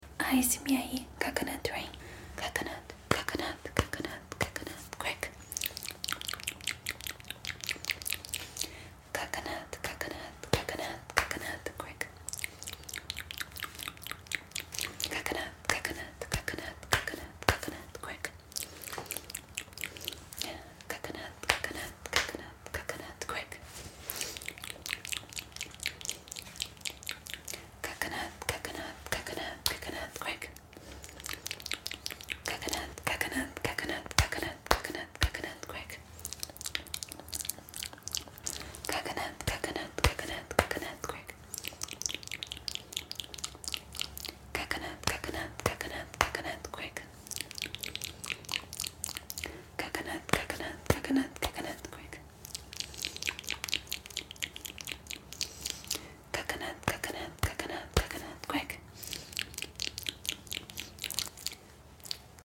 amo esse gatilho de asmr sound effects free download
coconut rain asmr